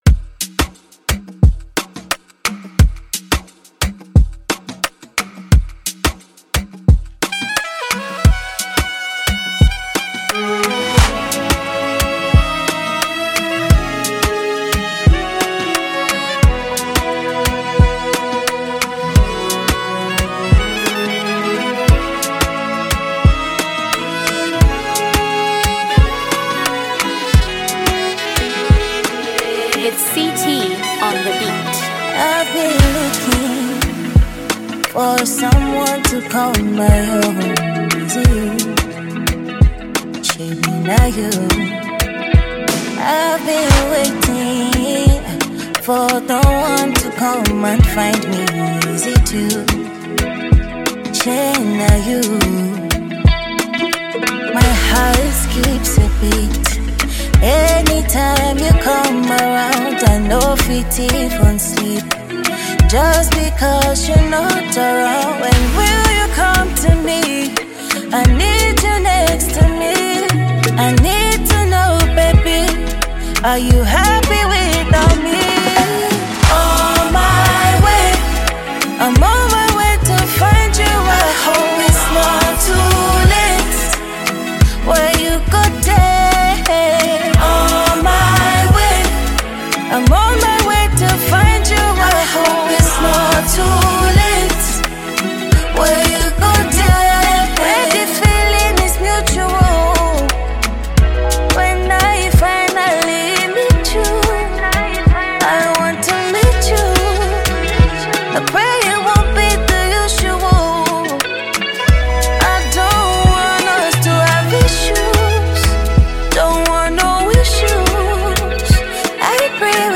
Ghanaian female singer-songwriter